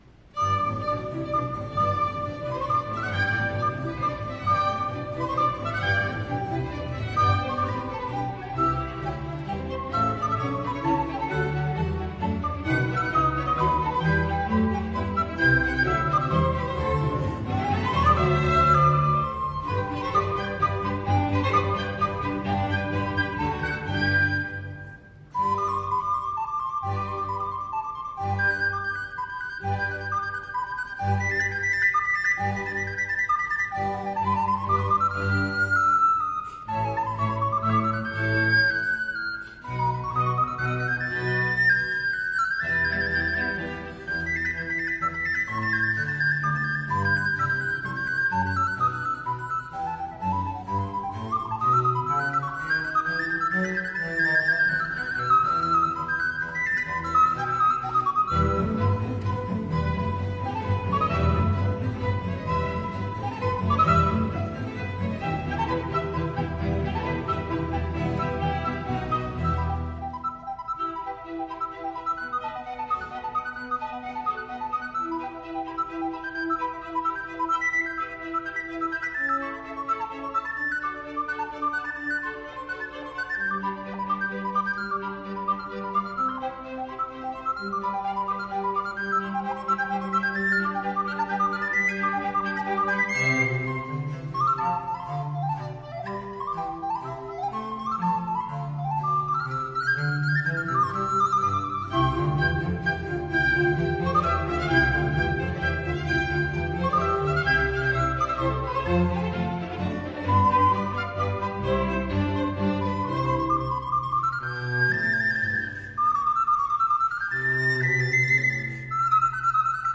VIVALDI, concerto rv443, flautino - LE SUEUR, les muses Clio Euterpe et Thalia.mp3